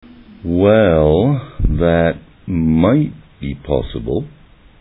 A conversation between a student and a professor.
Taking the context of the conversation and the way the professor spoke, you can understand that he feels it will be difficult for the students plan to succeed.